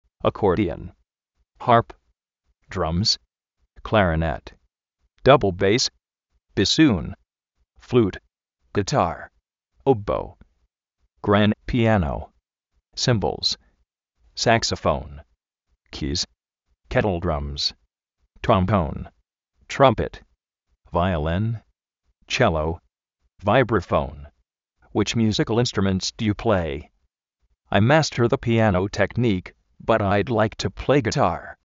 akórdion
jarp
dráms
dábl béis
(grand) piánou